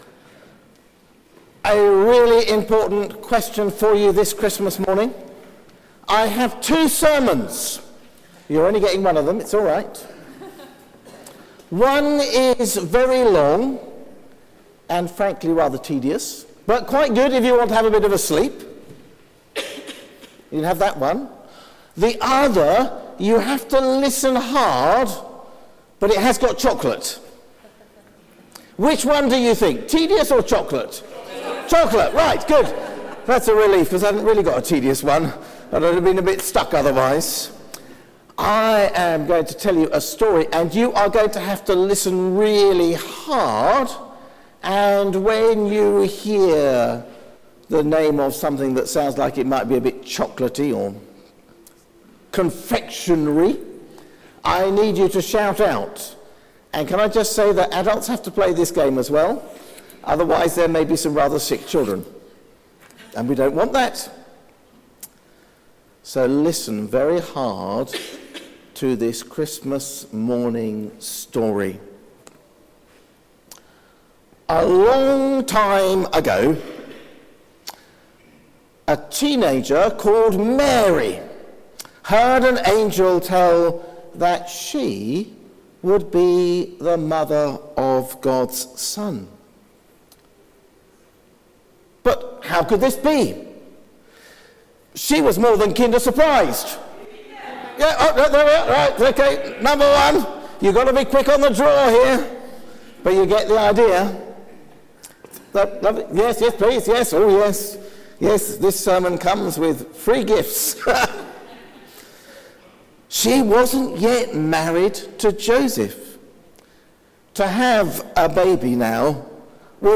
Sermon: Christmas celebrations | St Paul + St Stephen Gloucester
Bishop Robert Springett, 2019-12-25 (Luke 2:1-20)